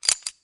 GrenadeReady.wav